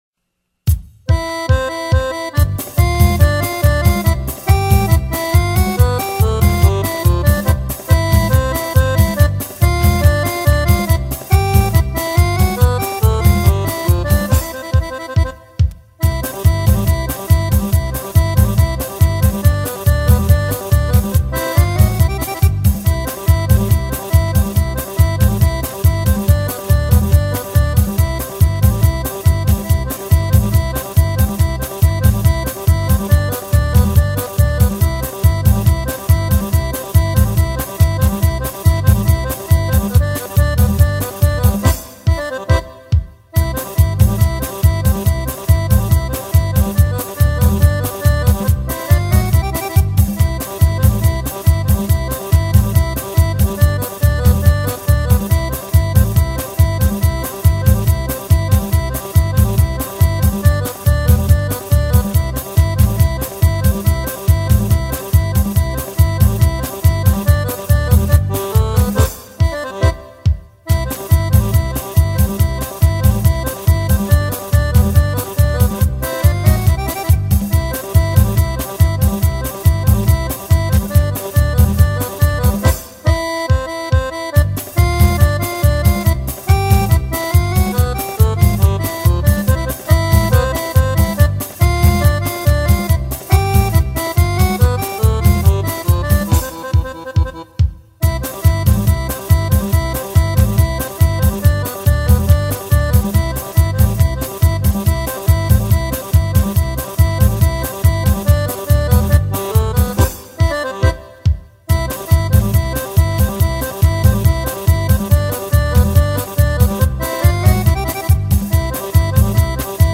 música